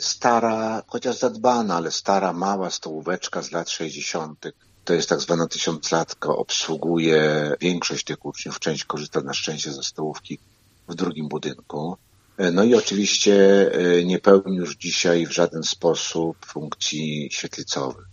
O obecnej placówce mówi wójt gminy Kadzidło, Dariusz Łukaszewski: